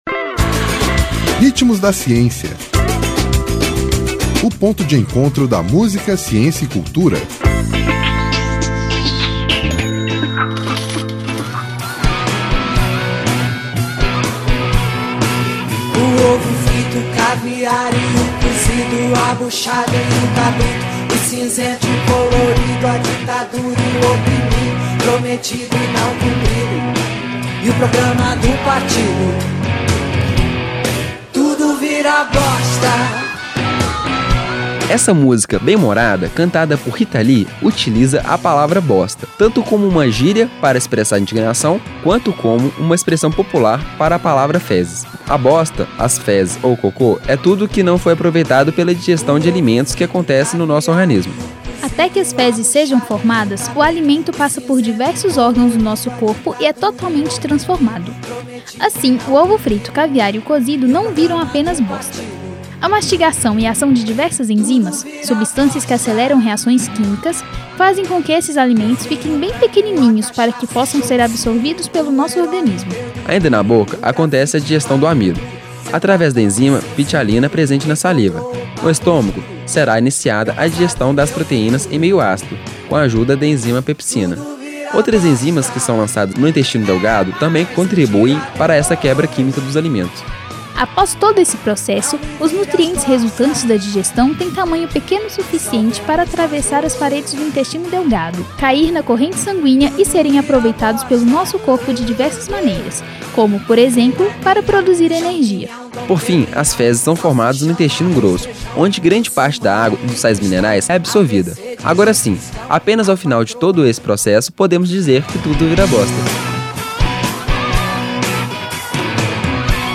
Essa música bem-humorada cantada por Rita Lee utiliza a palavra “bosta” tanto como uma gíria para expressar indignação, quanto como uma expressão popular para a palavra “fezes”. A bosta, as fezes ou o cocô é tudo o que não foi aproveitado pela digestão de alimentos que acontece no nosso organismo…
Intérprete: Rita Lee